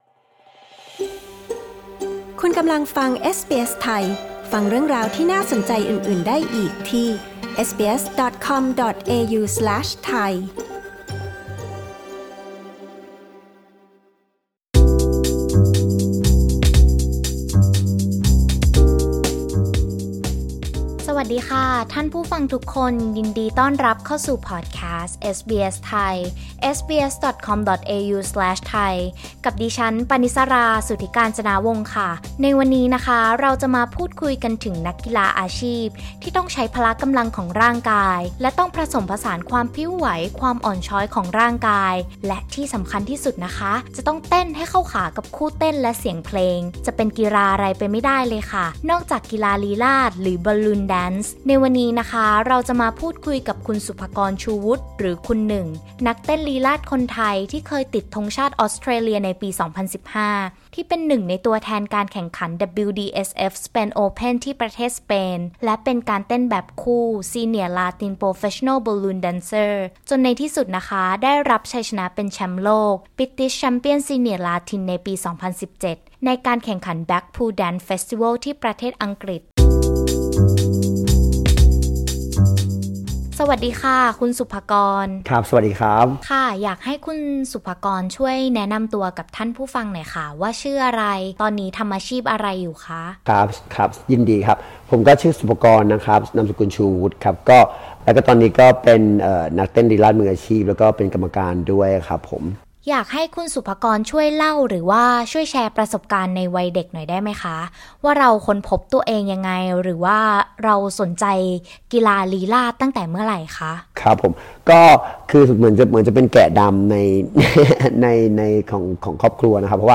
interview-france-dancer-final.mp3